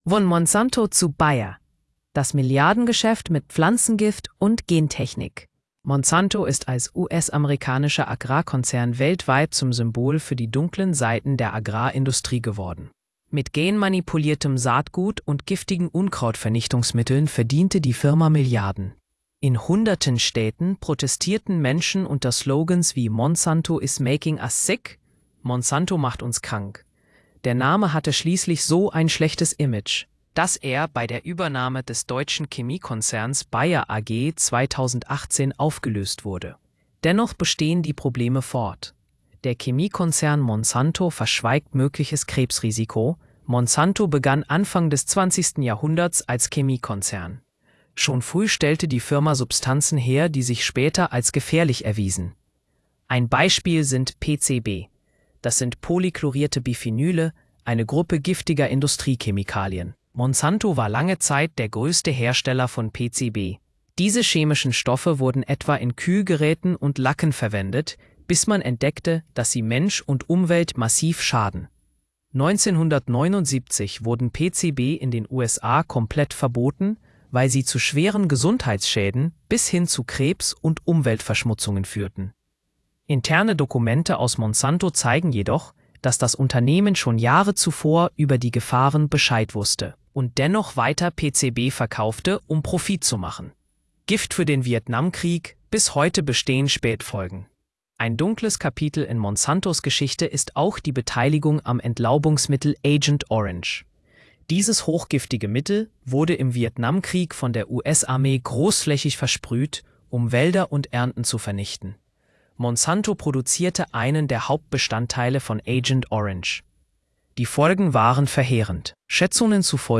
Hallgassa meg a cikk hangos változatát (mesterséges intelligencia által generált).